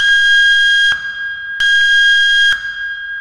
beepSiren.ogg